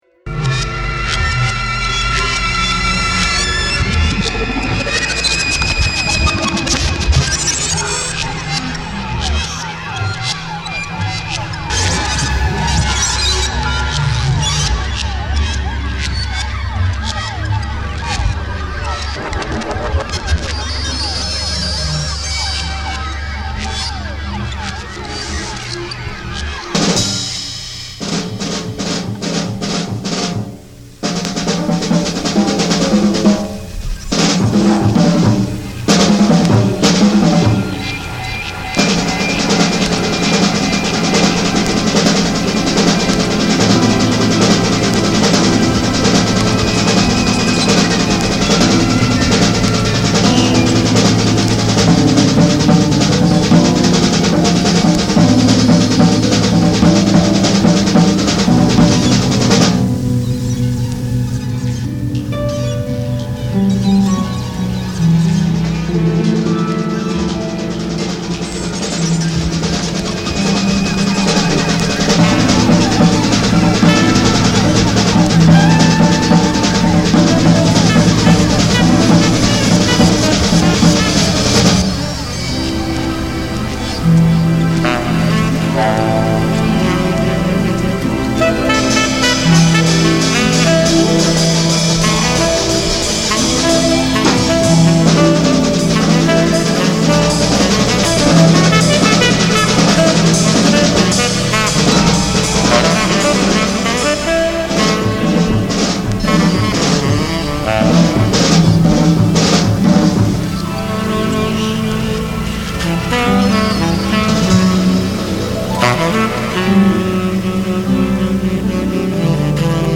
finely textured strings